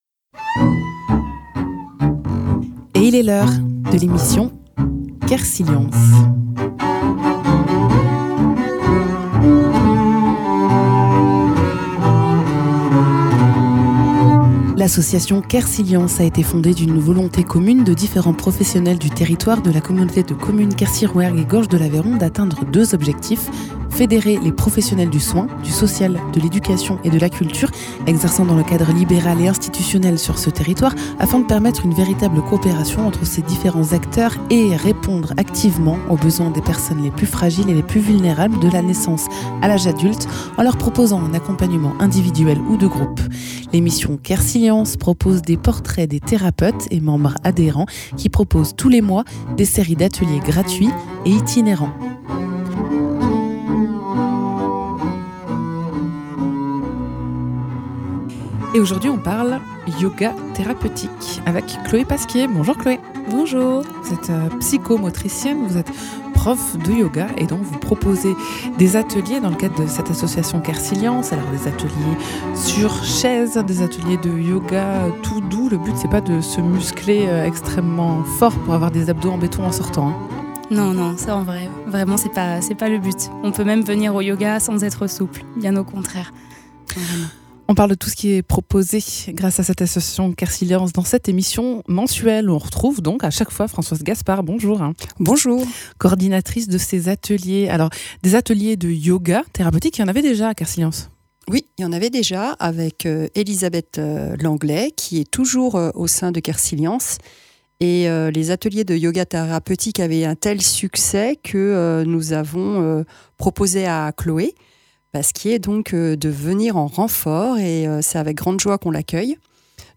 psychomotricienne et prof de yoga